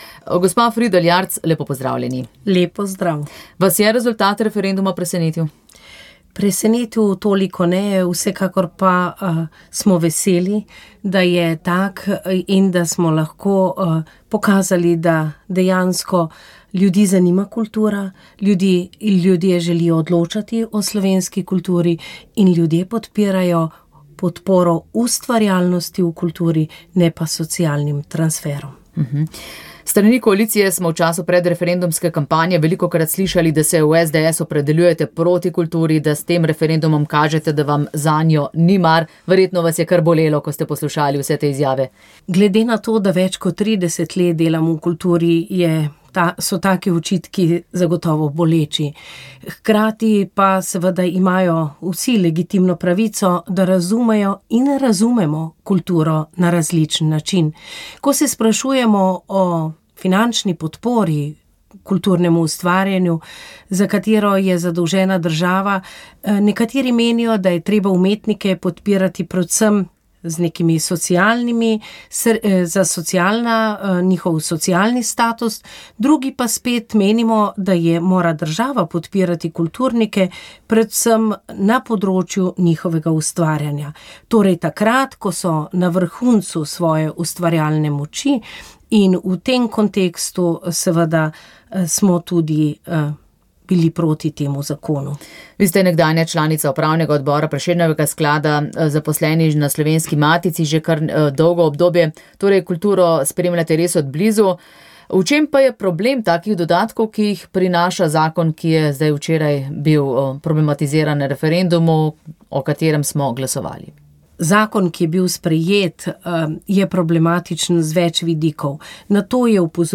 V kapeli Božjega usmiljenja Pod Krenom v Kočevskem Rogu je bila tradicionalna slovesnost za pobite domobrance in druge žrtve revolucionarnega nasilja. Sveto mašo je daroval upokojeni nadškof Anton Stres. V pridigi je opozoril, da sprava v slovenski družbi še vedno ni bila dosežena.